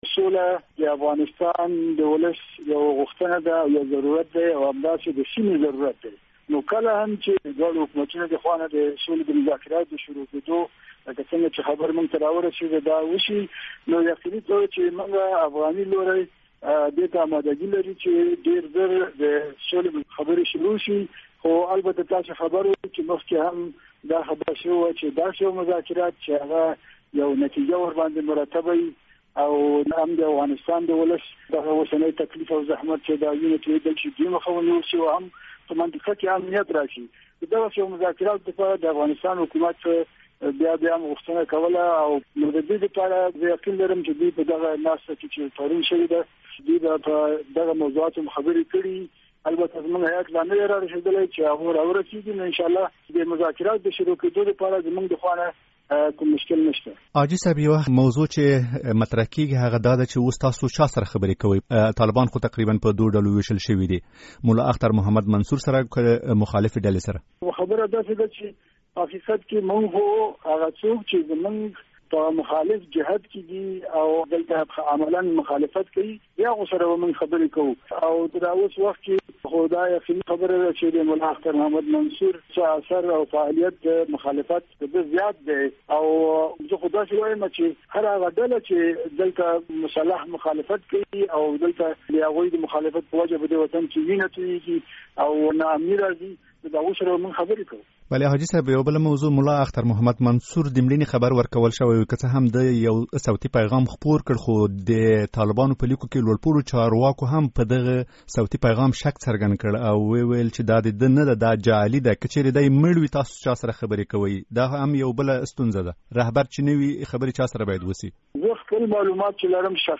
له حاجي دین محمد سره مرکه